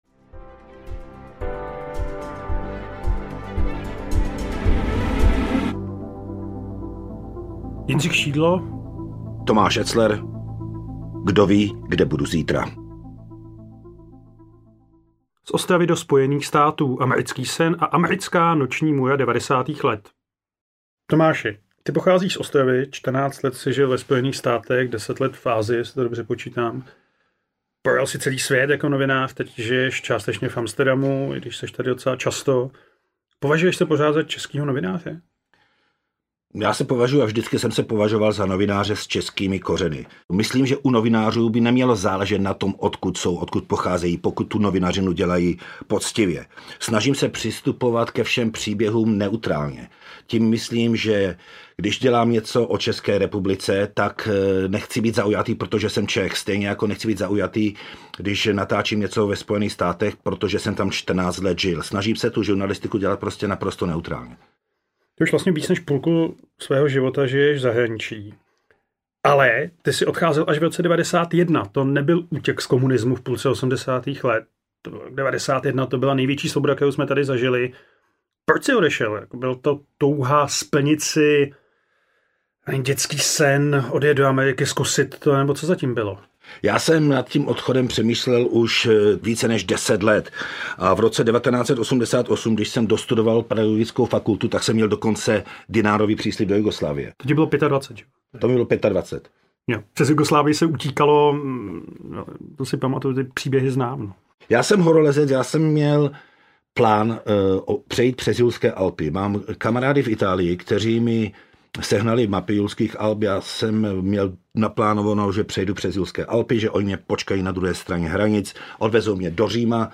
Kdo ví, kde budu zítra audiokniha
Ukázka z knihy
A proč vlastně lidé odcházejí do zahraničí?Dva ostřílení novináři spolu diskutují o řadě vážných i méně vážných témat.
• InterpretJindřich Šídlo, Tomáš Etzler